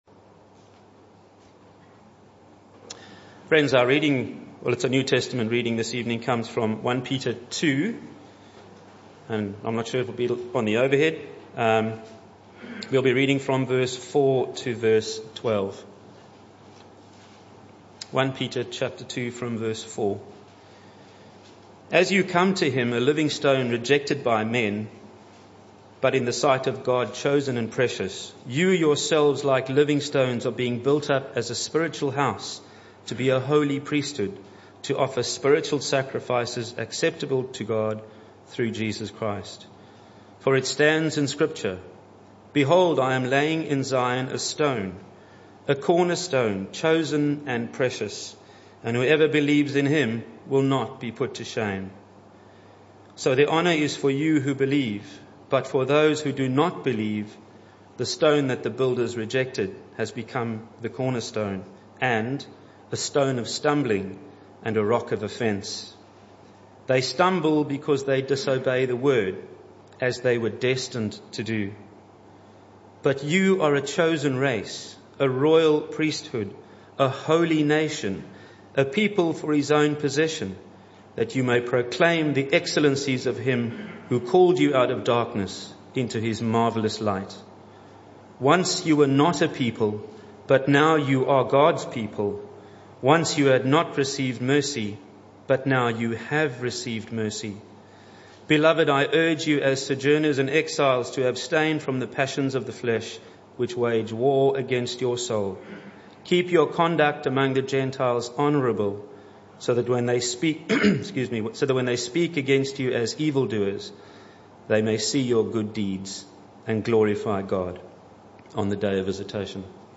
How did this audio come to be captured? This talk was a one-off that took place on Reformation Sunday 2018. Passage: 1 Peter 2:4-12 Service Type: Evening Service